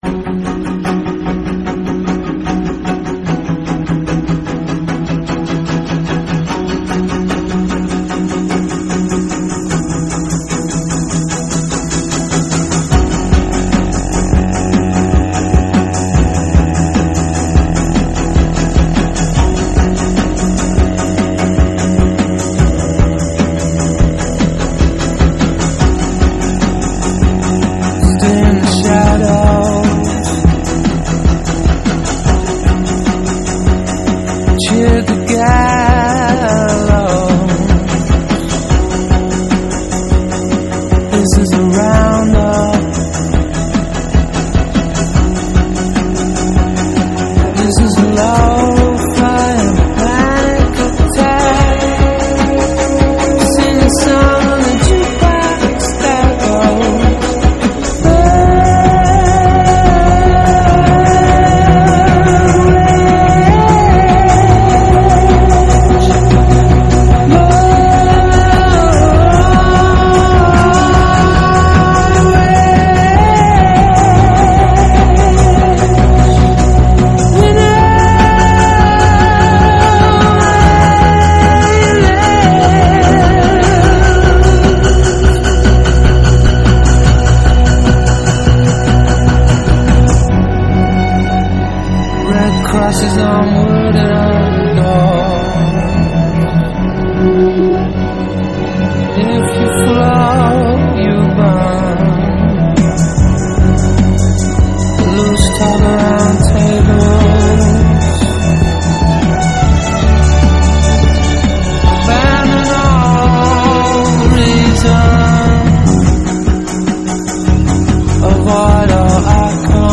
Art Rock, Experimental, Orchestral